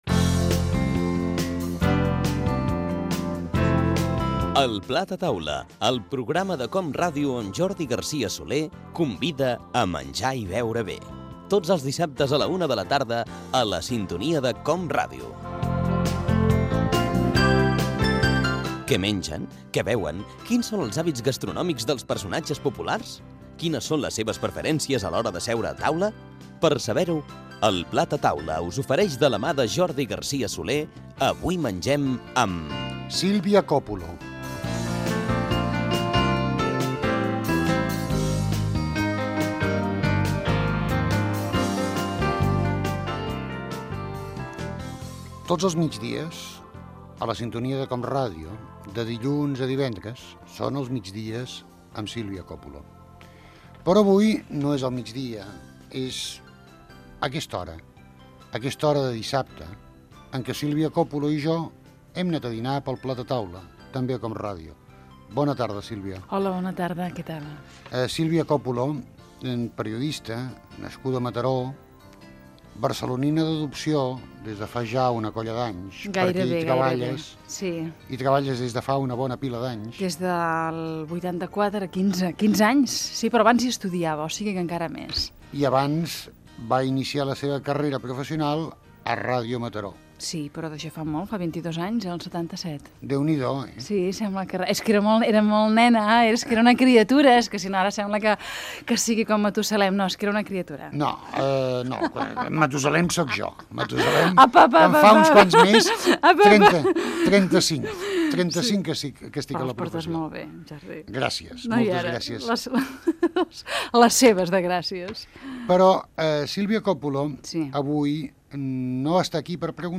Careta, presentació i fragment d'una entrevista